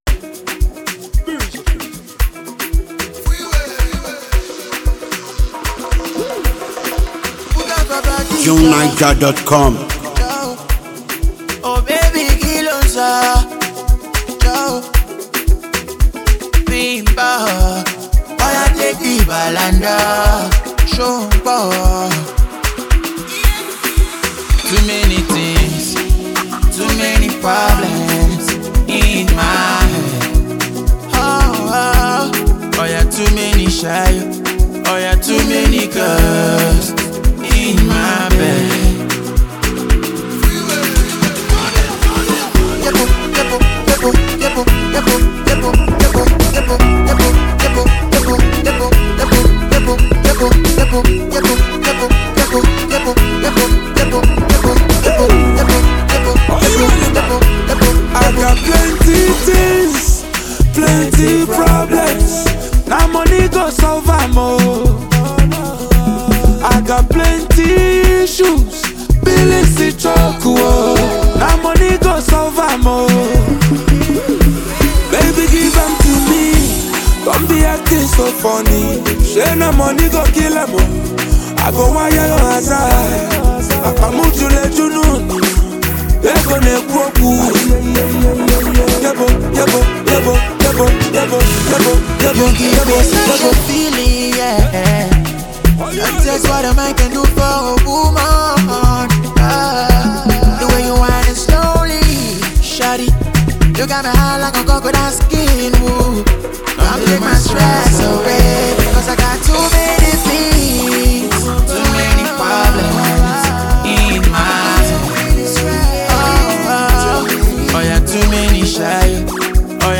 It’s the ideal choice for relaxing and moving to the music.